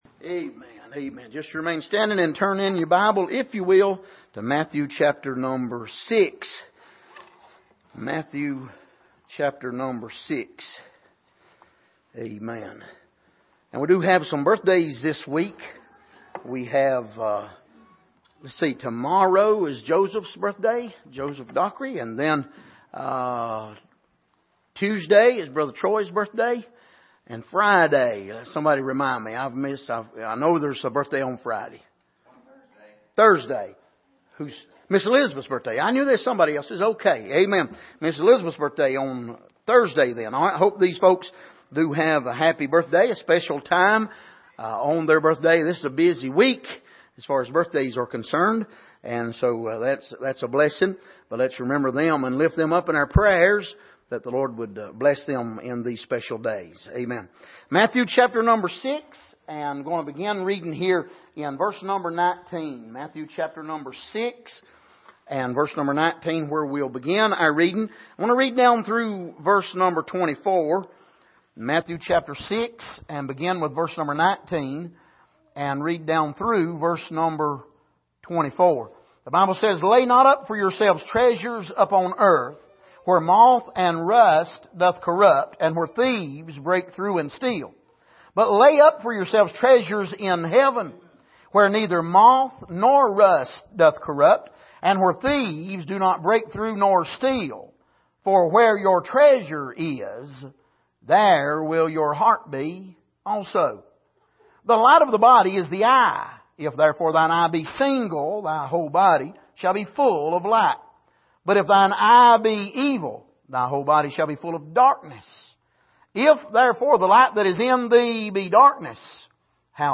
Passage: Matthew 6:19-24 Service: Sunday Morning